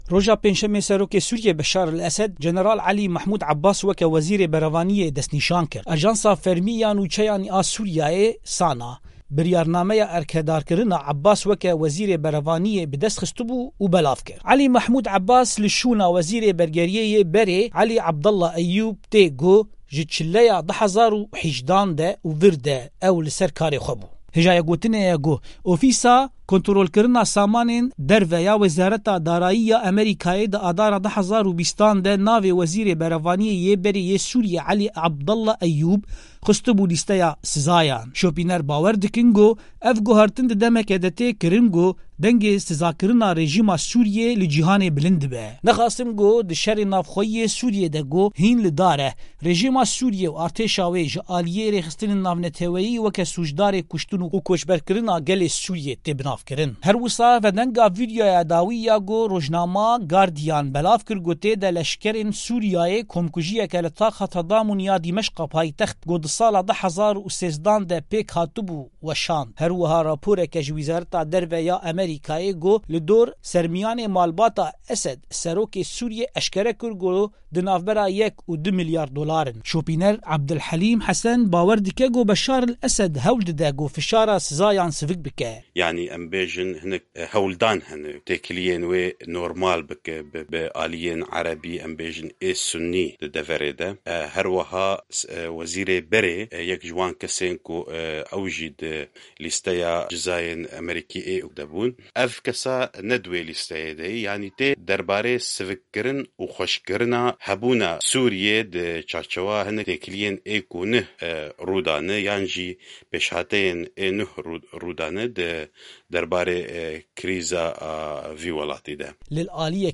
دەقی ڕاپۆرتی